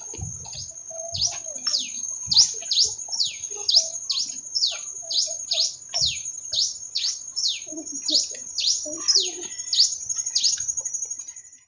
Black-and-rufous Warbling Finch (Poospiza nigrorufa)
Life Stage: Adult
Location or protected area: Parque Natural Municipal Ribera Norte (San Isidro)
Condition: Wild
Certainty: Observed, Recorded vocal